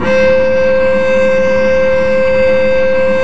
Index of /90_sSampleCDs/Roland - String Master Series/STR_Vc Marc&Harm/STR_Vc Harmonics